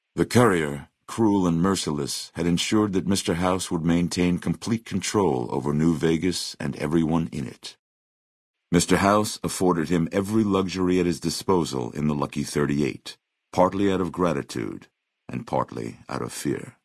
Category:Fallout: New Vegas endgame narrations Du kannst diese Datei nicht überschreiben.